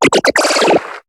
Cri de Mucuscule dans Pokémon HOME.